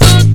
GTR HIT.wav